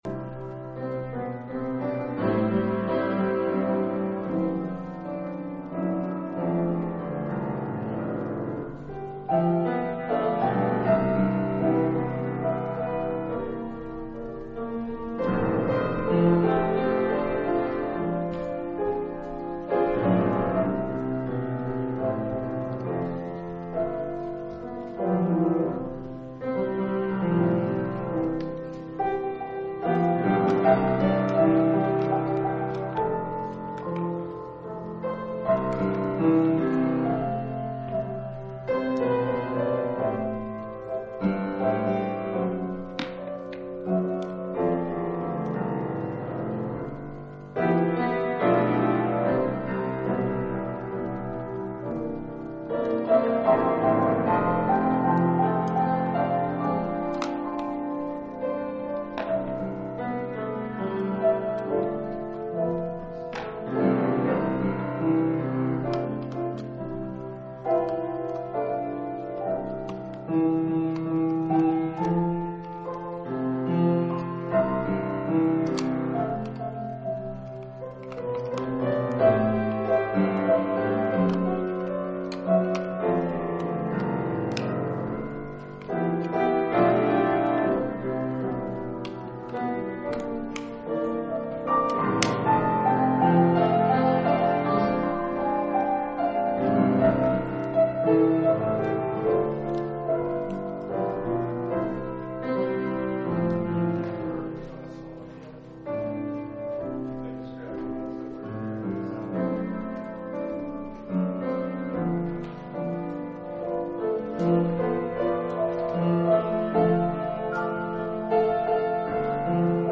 The Essentials Part 4 – Last Trumpet Ministries – Truth Tabernacle – Sermon Library